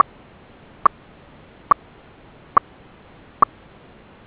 • Fast single pulse signal: collar has recognized a mortality event. Your animal is immobile and maybe dead (mortality mode: !..!..!..!)
Sounds of all beacon modes: